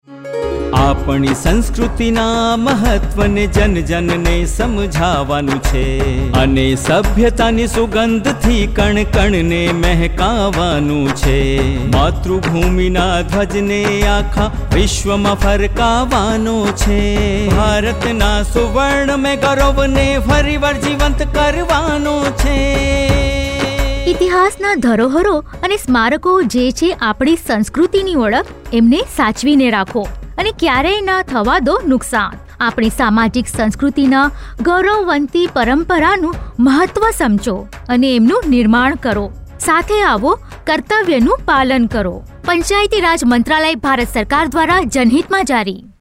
63 Fundamental Duty 6th Fundamental Duty Preserve composite culture Radio Jingle Gujrati